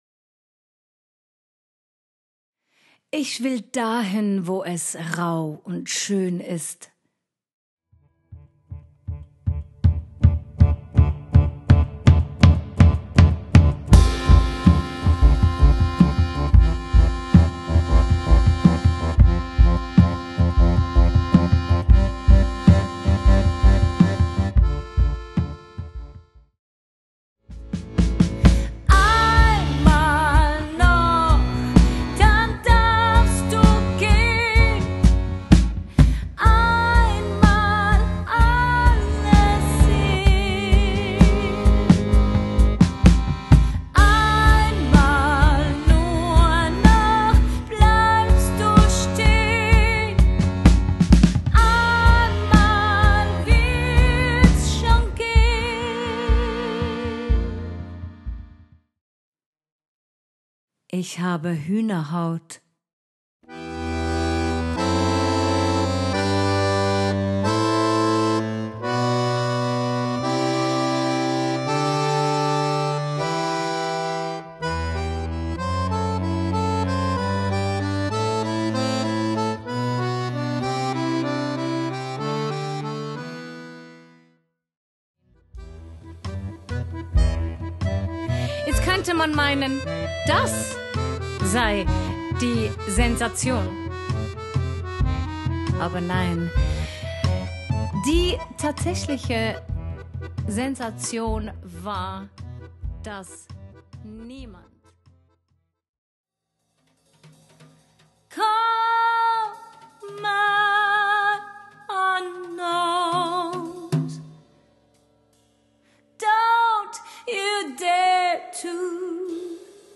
STIMME
AKKORDEON
SCHLAGZEUG